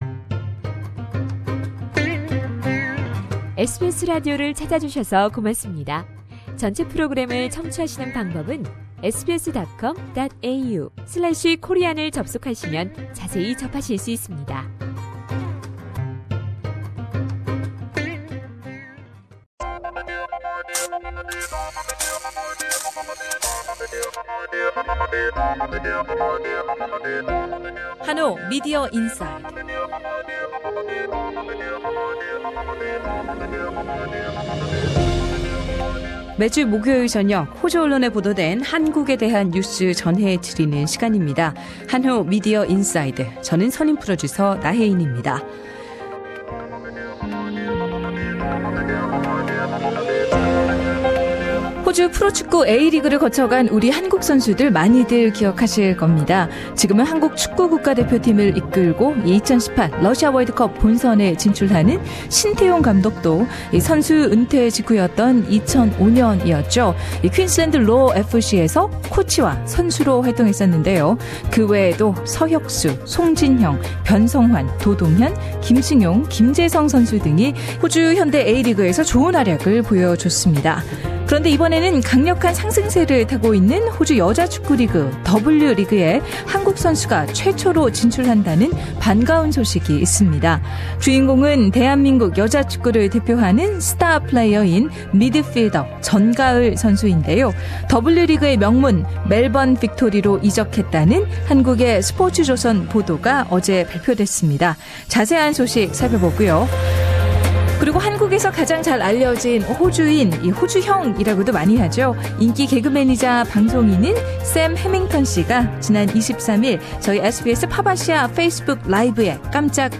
한국의 코메디언이자 방송인인 샘 해밍턴 씨가 지난 23일 SBS PopAsia 페이스북 라이브에 출연해 한국과 인연과 방송 활동등에 대해서 이야기했다.